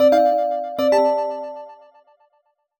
jingle_chime_08_positive.wav